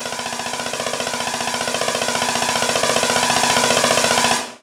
snarefill4.ogg